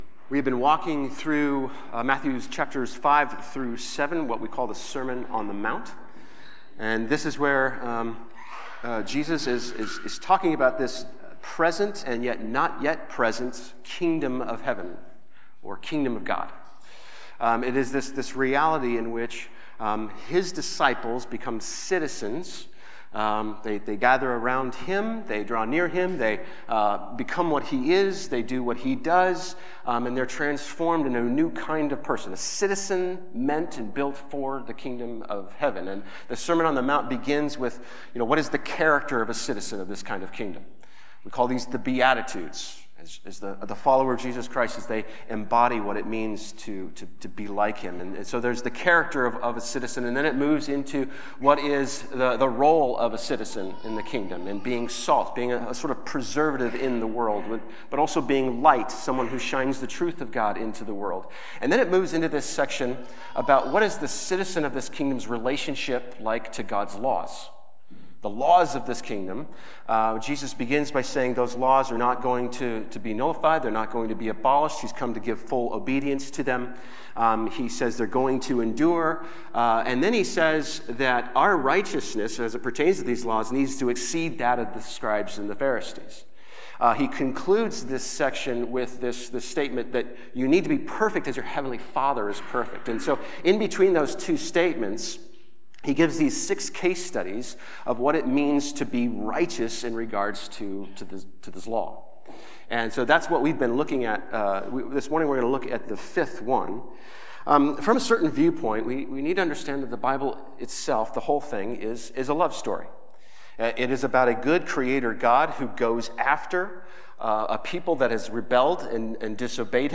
New Community Church - Sermon Audio Podcast - Sermon on the Mount Series (Matthew 5:38-42) | Free Listening on Podbean App